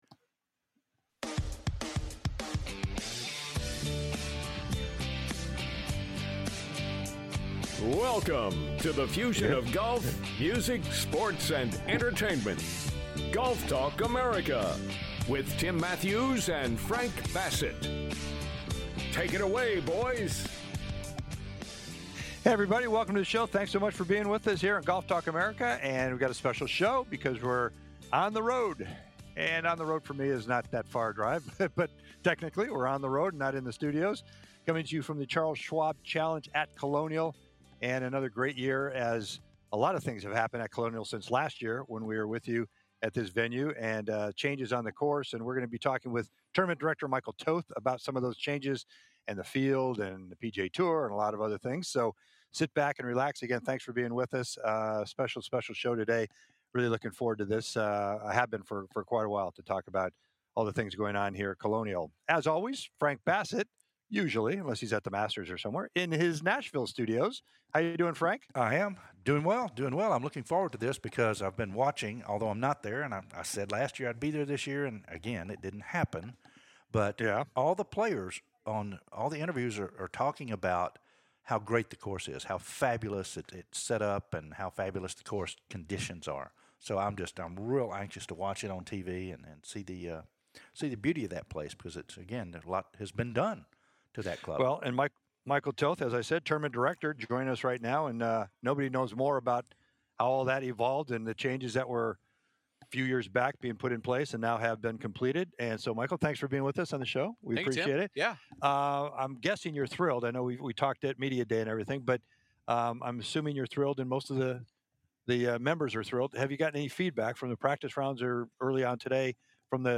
"LIVE" FROM THE 2024 CHARLES SCHWAB CHALLENGE AT COLONIAL COUNTRY CLUB